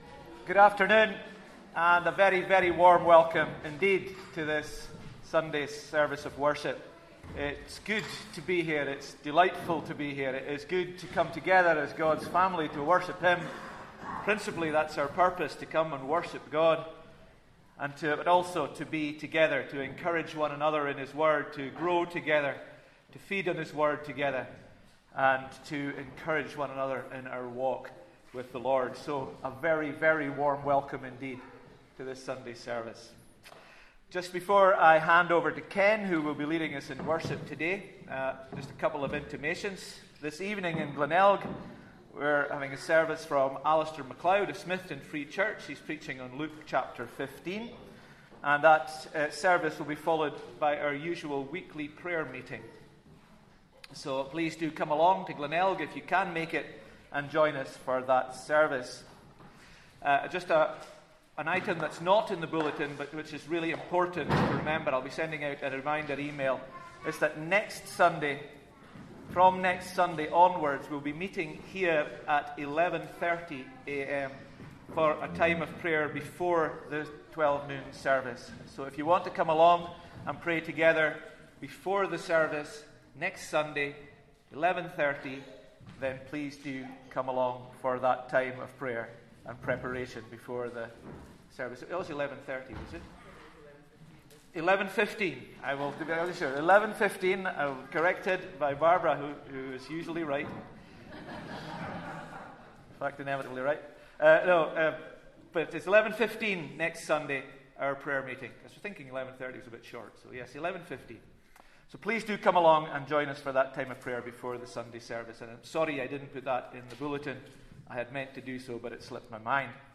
Church Servuice 25th August 2024